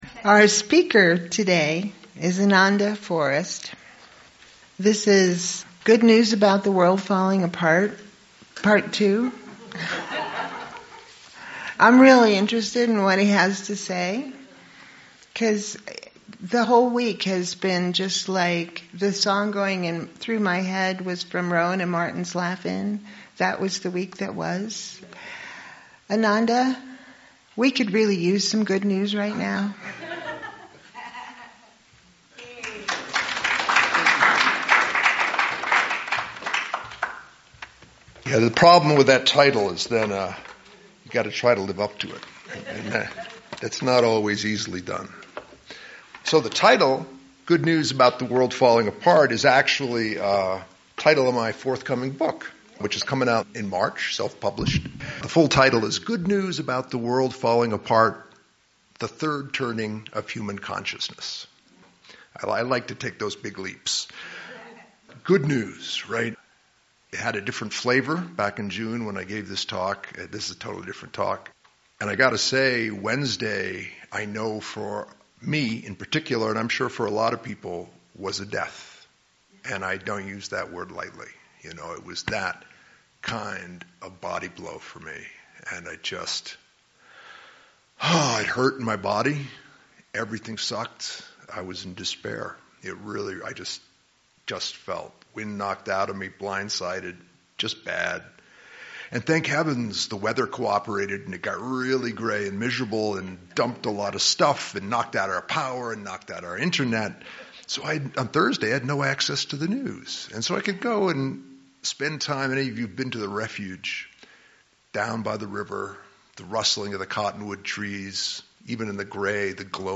Talk given at the Celebration in Santa Fe, NM in November, 2024 about the recent presidential election.